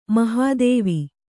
♪ mahādēvi